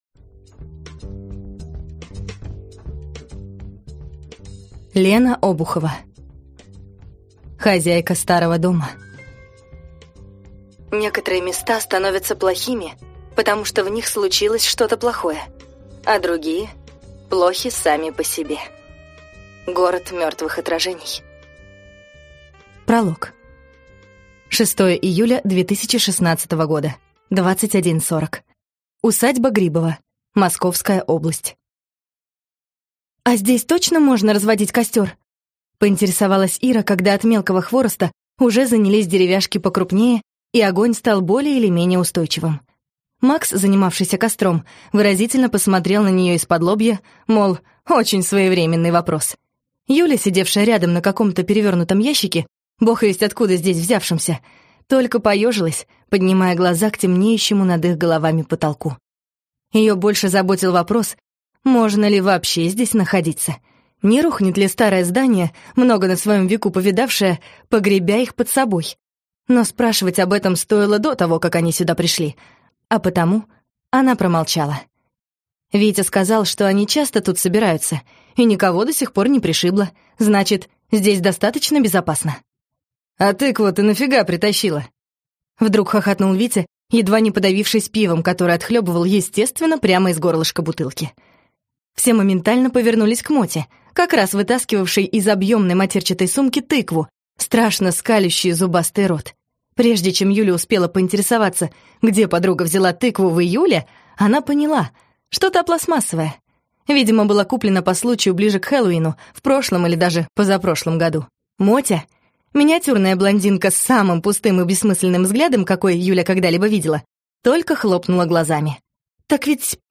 Скачать аудиокнигу Хозяйка старого дома